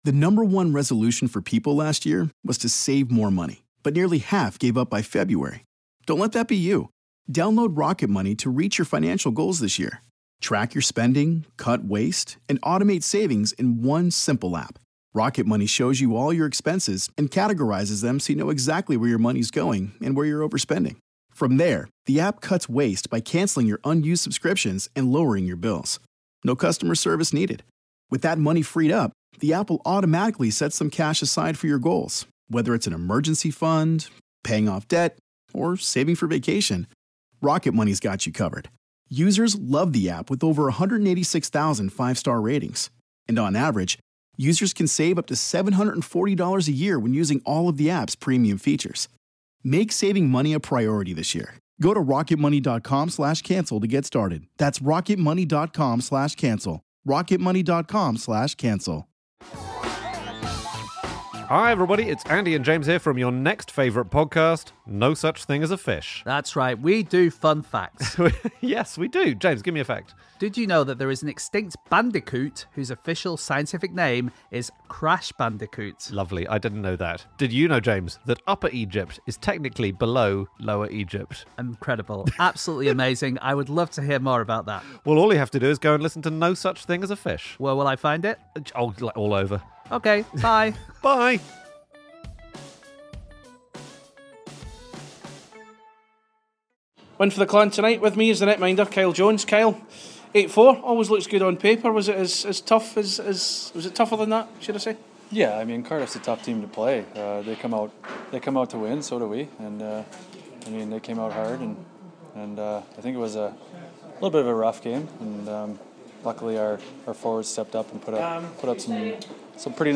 Hear what he said post match here